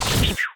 hit3.wav